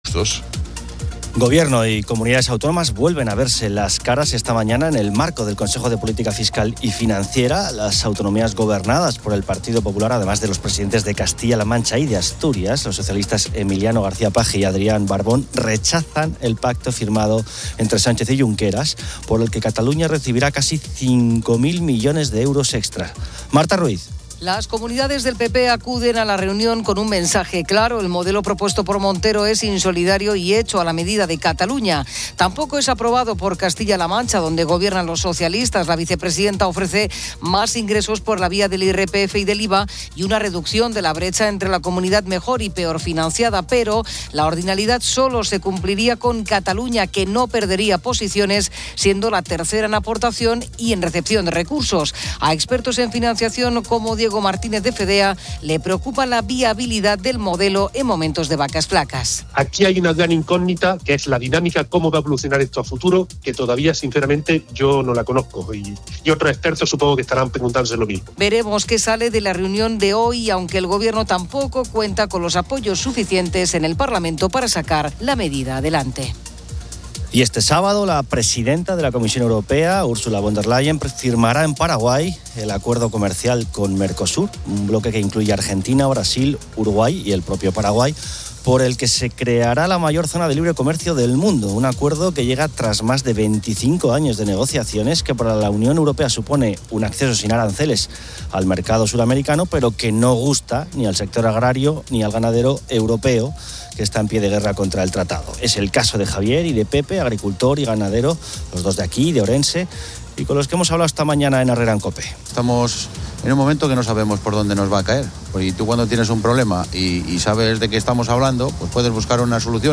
La tertulia analiza lo que la gente finge que le gusta por presión social, el debate sobre Leonardo DiCaprio que no ve sus películas, y el descenso de piropos en parejas.